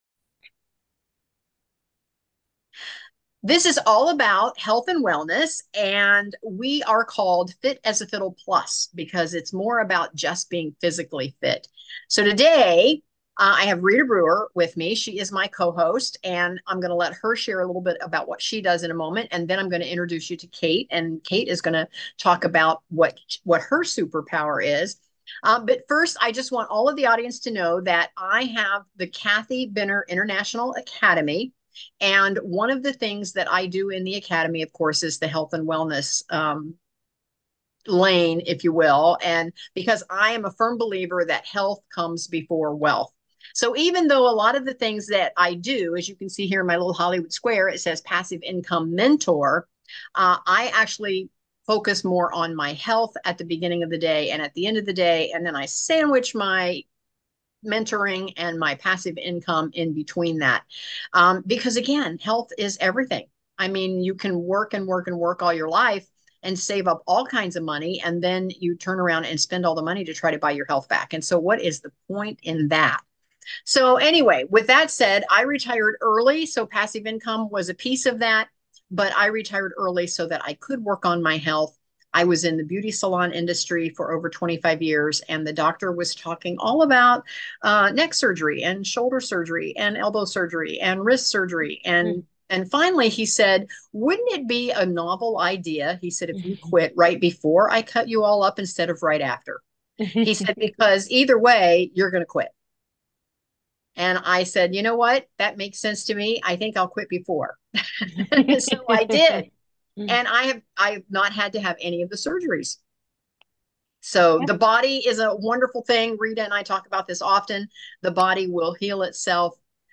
Join us each month as we interview amazing health and wellness industry practitioners.